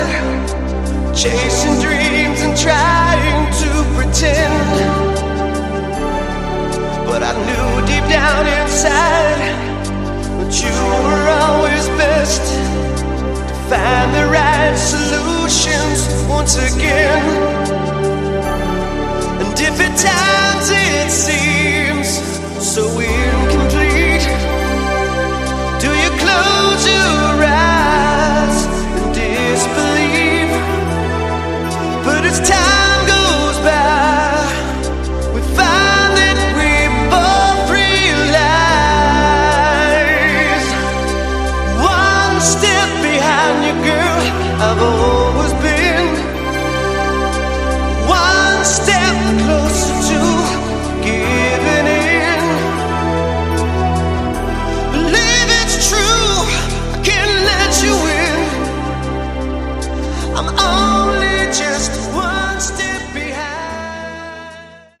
Category: AOR
Ballad